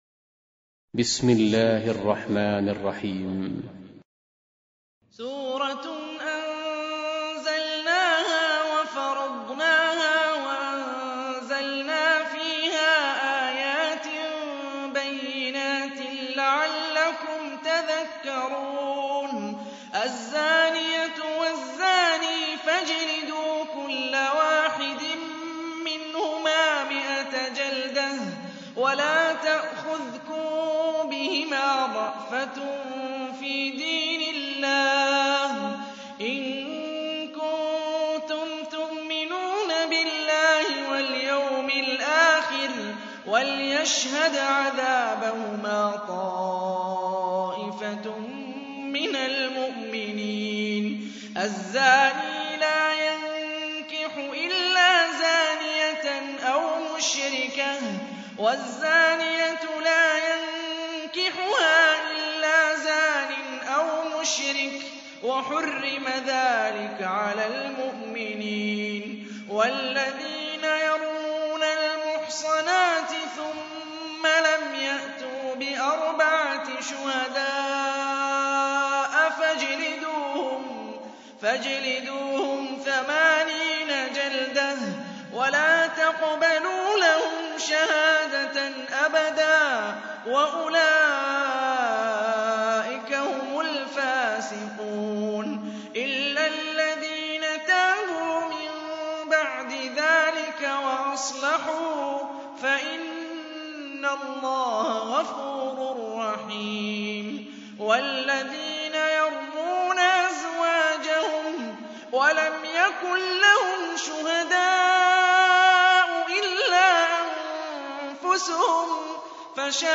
24. Surah An-N�r سورة النّور Audio Quran Tarteel Recitation
Surah Repeating تكرار السورة Download Surah حمّل السورة Reciting Murattalah Audio for 24.